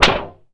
metal_tin_impacts_wobble_bend_04.wav